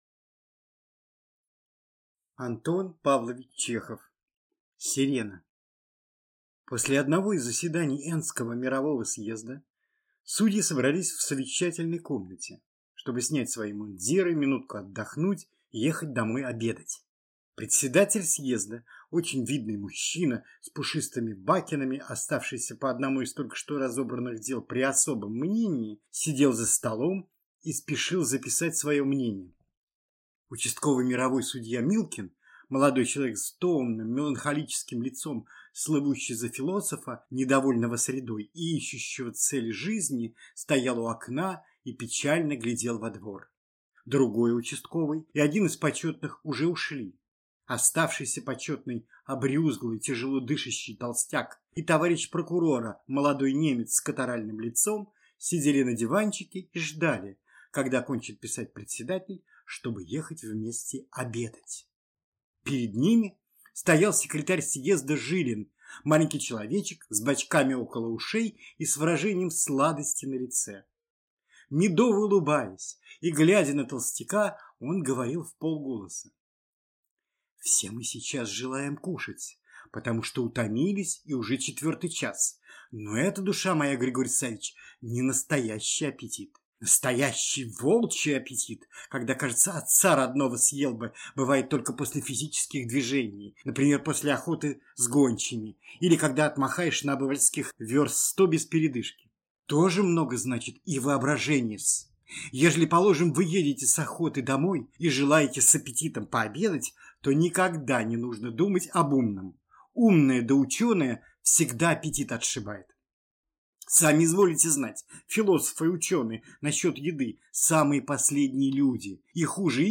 Аудиокнига Сирена | Библиотека аудиокниг